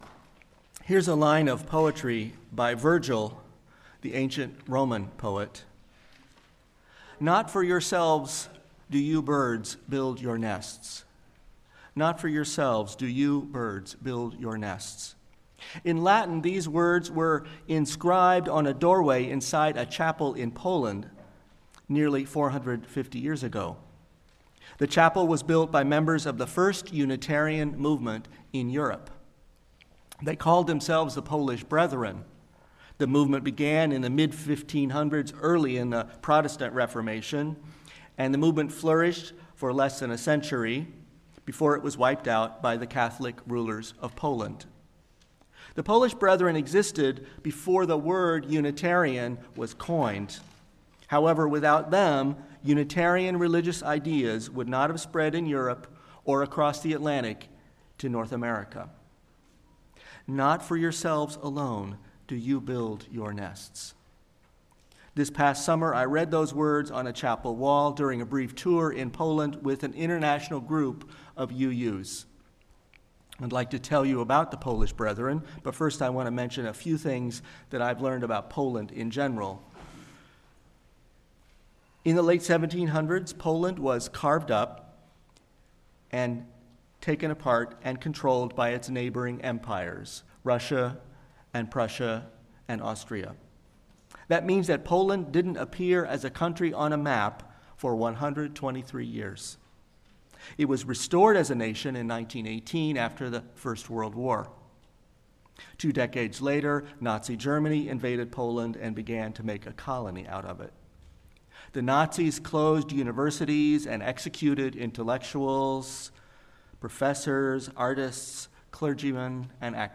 Sermon-Utopian-Unitarians-in-1569.mp3